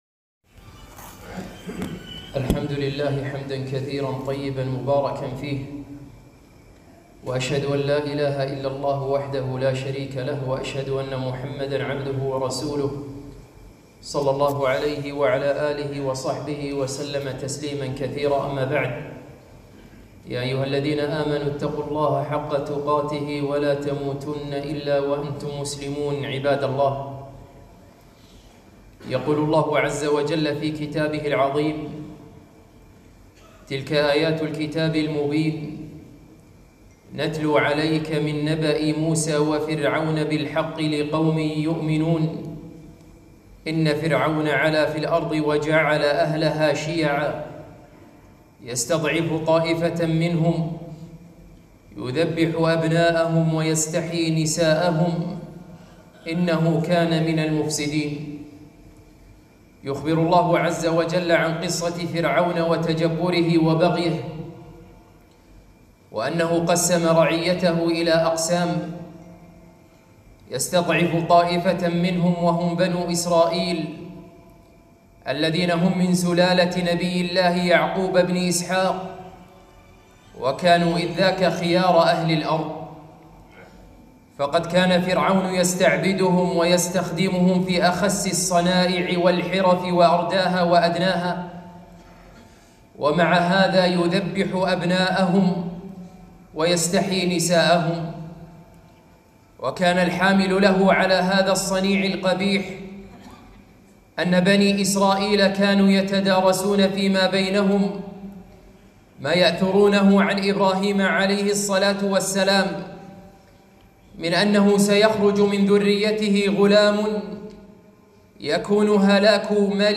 خطبة - قصة موسى وعاشوراء